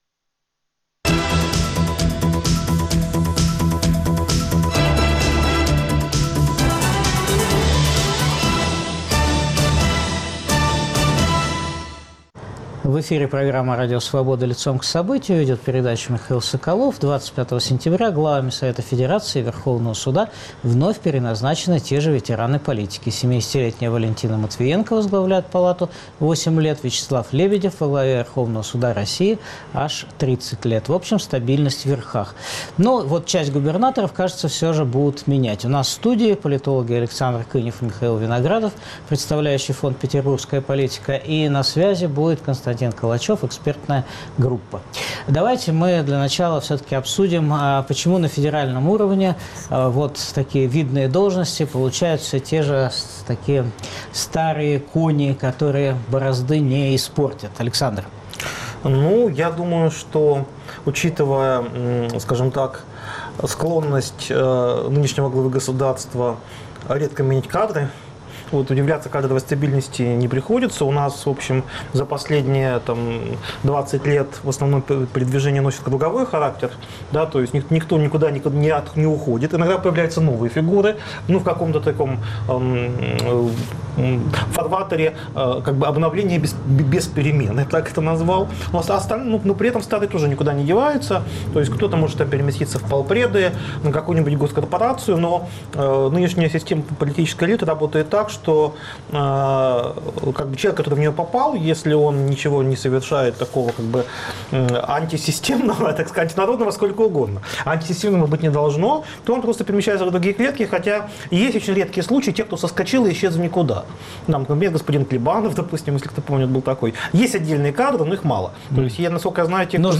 Почему на федеральном уровне должности получают все те же персоны", а на региональном фактически назначаются губернаторами "молодые технократы"? Обсуждают политологи